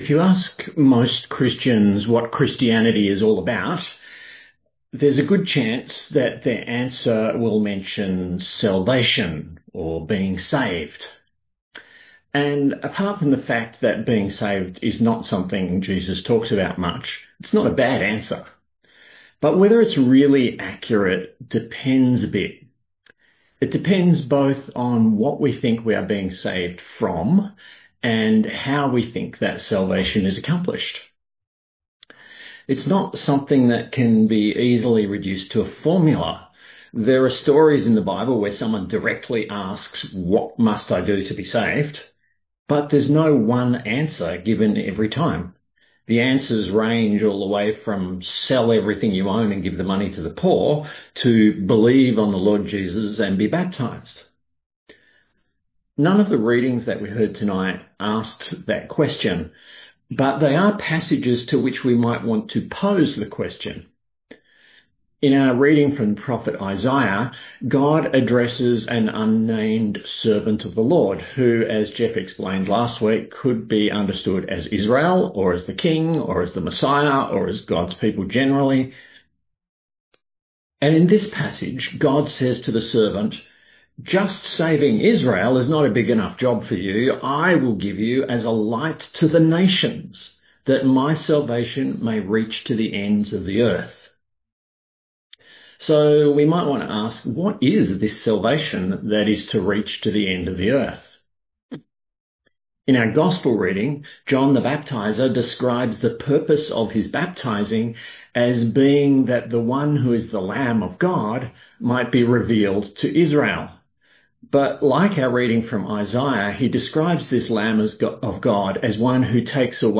A sermon on Isaiah 49:1-7; Psalm 40:1-11; & John 1:29-42